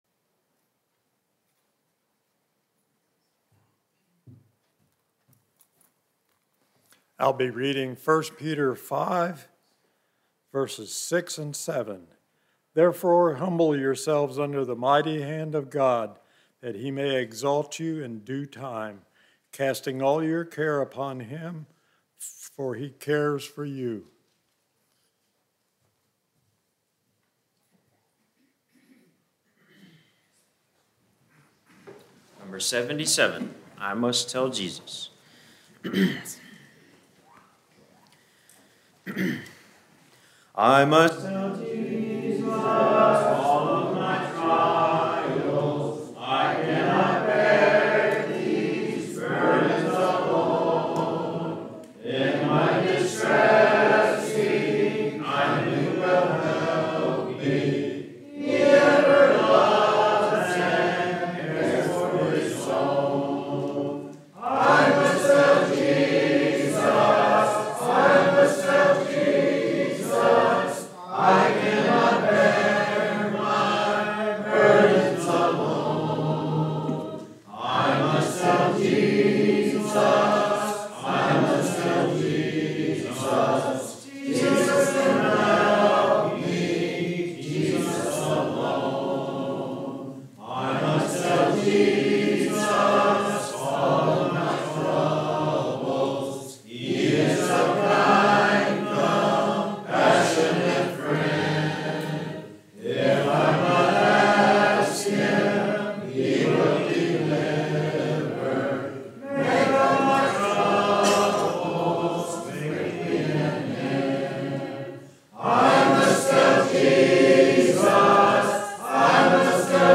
Sun PM Sermon – 5th Sunday night sing – 12-31-23